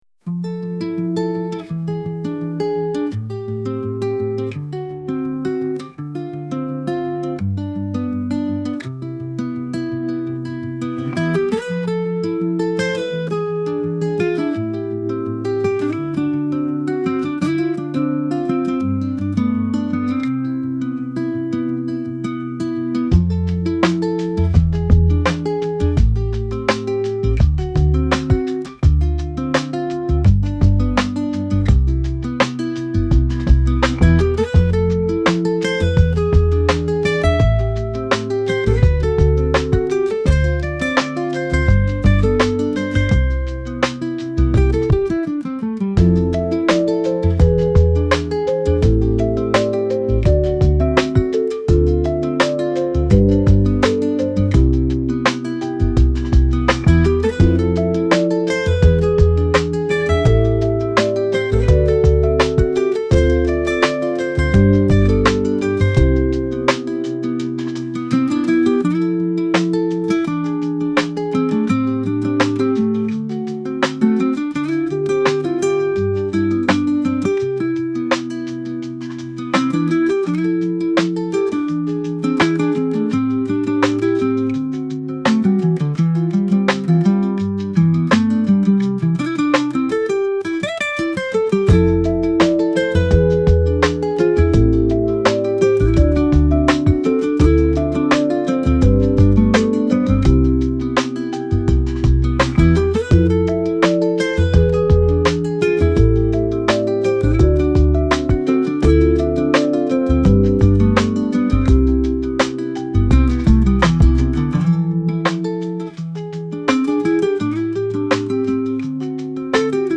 lo-fi かっこいい どこか懐かしい夏 悲しい